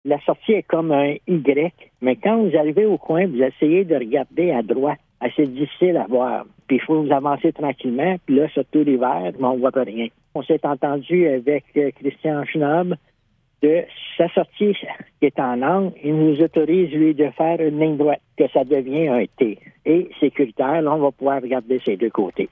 Le maire, Robert Bergeron, explique :